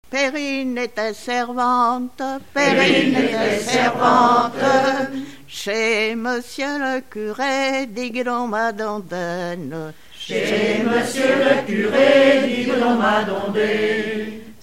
Genre laisse
Enquête Arexcpo en Vendée-Pays Sud-Vendée